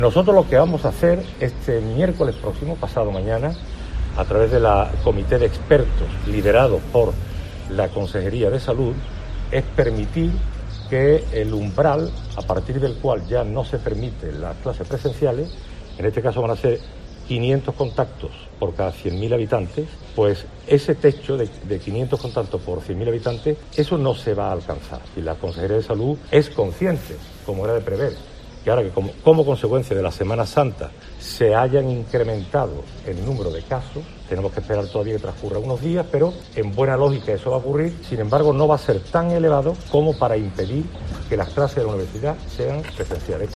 En este sentido y en declaraciones a los periodistas en La Rambla (Córdoba), donde ha presentado la nueva marca promocional que identificará a los productos realizados por el sector artesano, Velasco ha explicado que este mismo lunes ha hablado telefónicamente con "varios rectores" de universidades andaluzas, "para confirmarles que van a tener la posibilidad de continuar con las clases presenciales durante este último cuatrimestre" del curso.